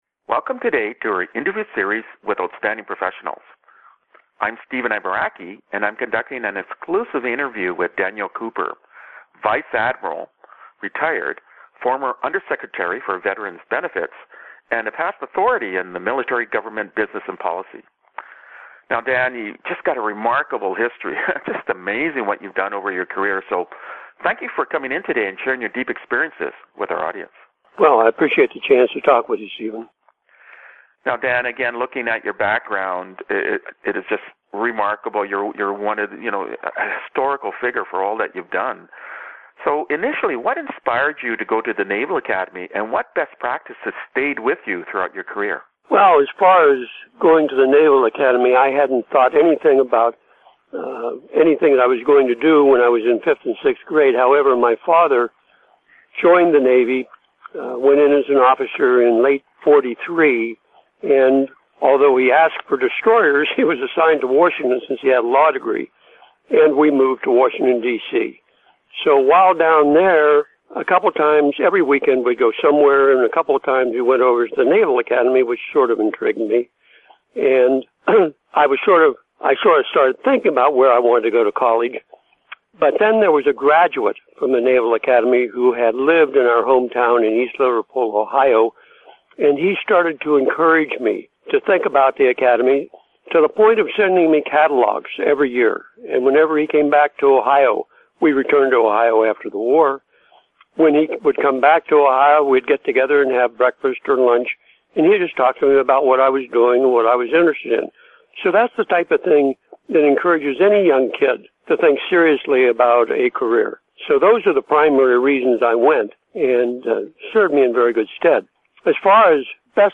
Interview Time Index (MM:SS) and Topic